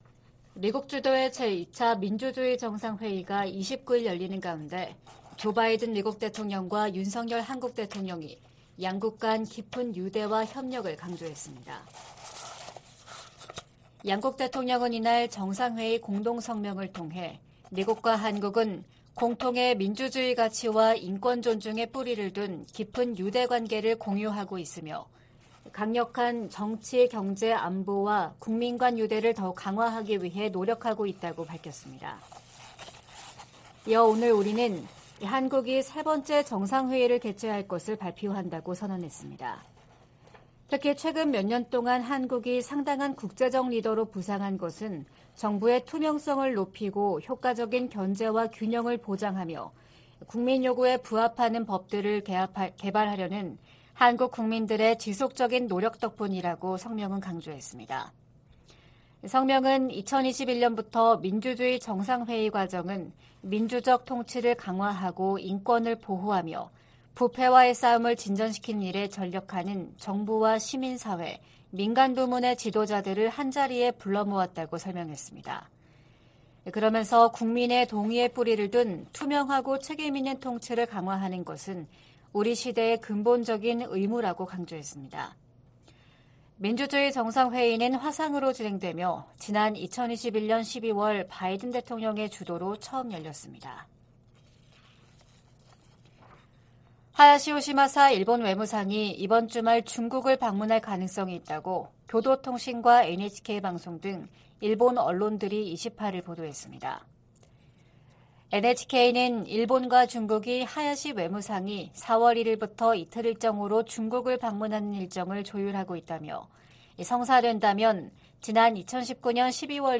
VOA 한국어 '출발 뉴스 쇼', 2023년 3월 30일 방송입니다. 백악관은 북한의 전술핵탄두 공개에 국가 안보와 동맹의 보호를 위한 준비태세의 중요성을 강조했습니다. 국무부는 북한의 '핵 공중폭발 시험' 주장에 불안정을 야기하는 도발행위라고 비난했습니다. 전문가들은 북한이 핵탄두 소형화에 진전을 이룬 것으로 평가하면서 위력 확인을 위한 추가 실험 가능성이 있다고 내다봤습니다.